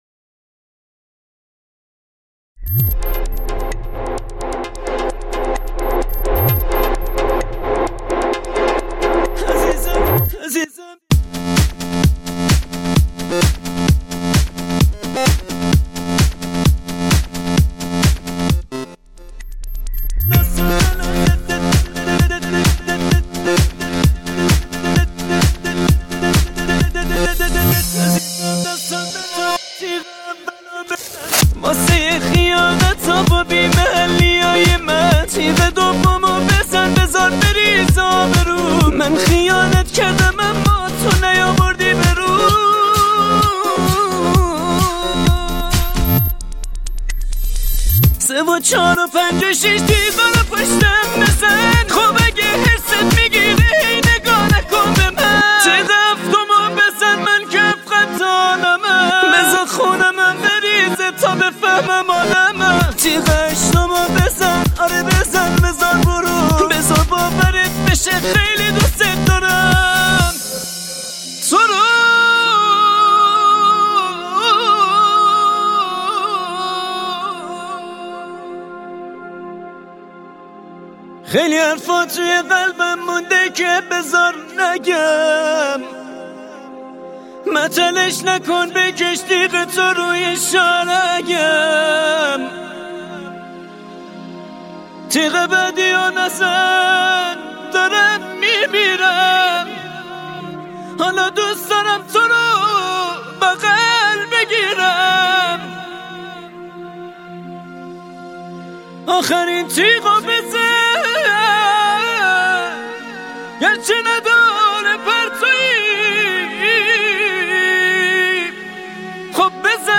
+ ریمیکس بندری اضافه شد